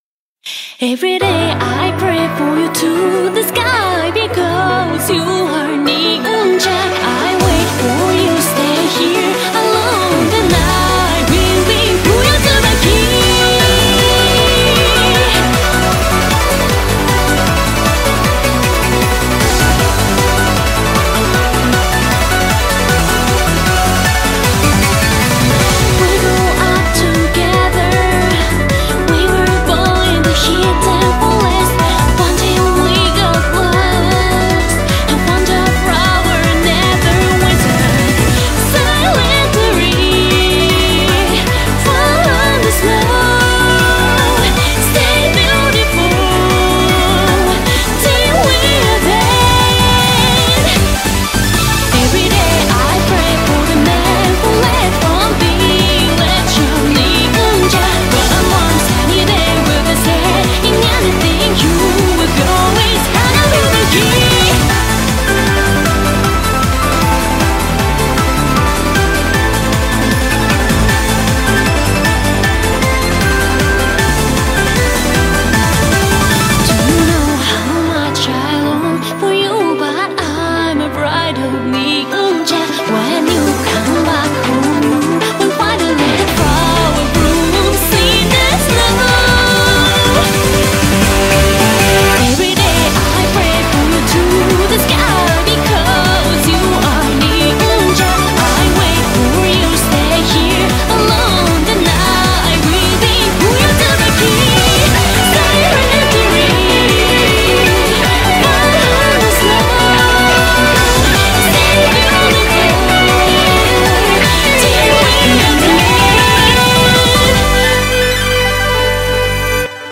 BPM155
Audio QualityPerfect (Low Quality)